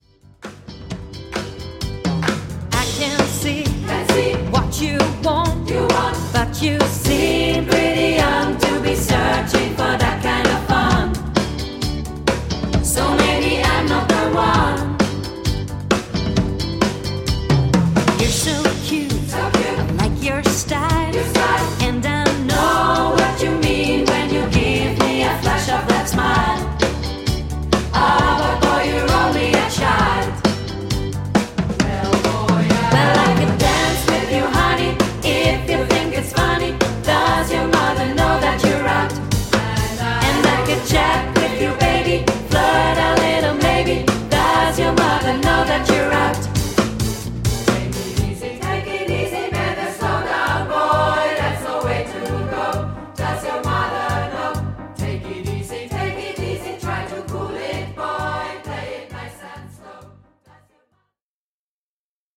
Band , Playback